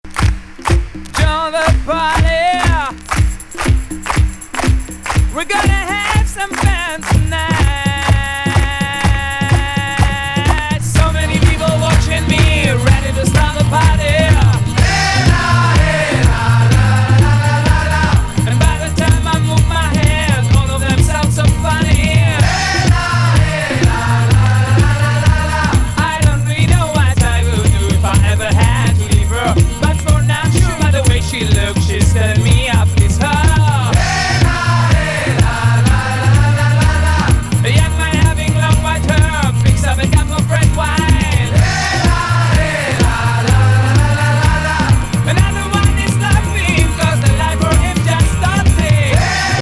サイケ・ムード充満!